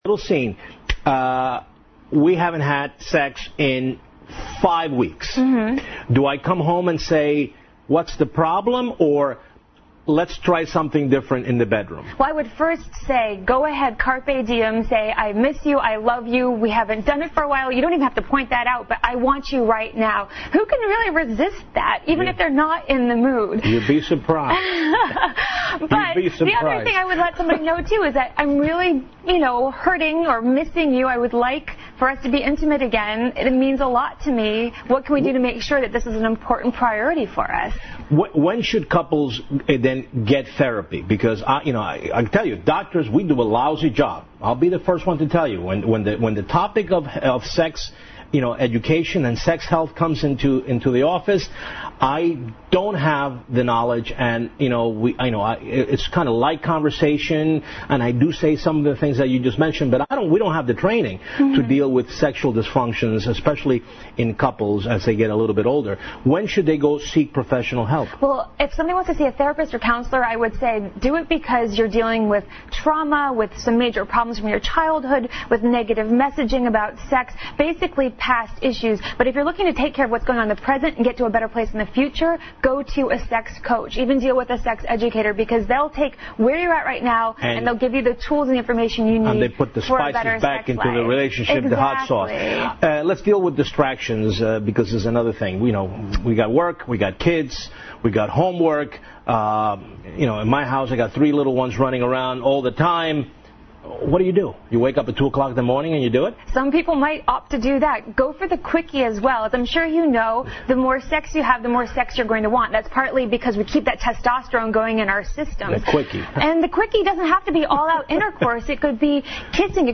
访谈录[Interview]2007-10-04:Rekindle Your Sex Life 重燃激情(2) 听力文件下载—在线英语听力室